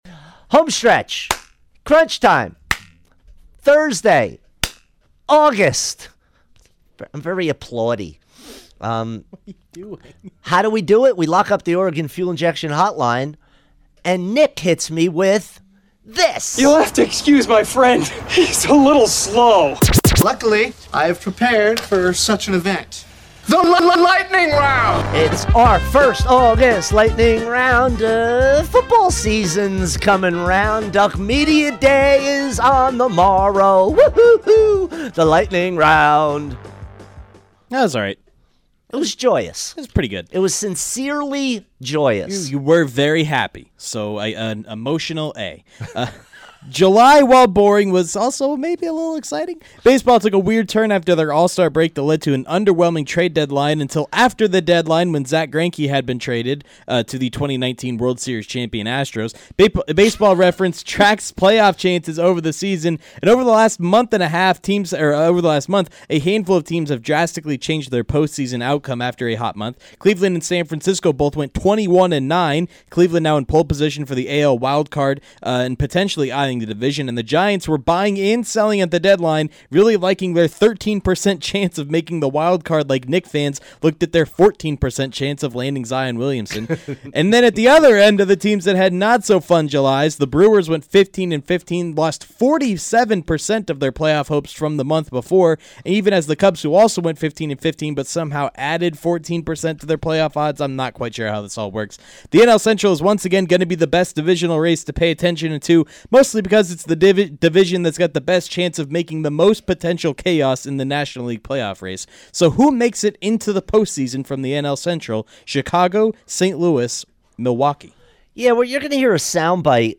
rapid-fire style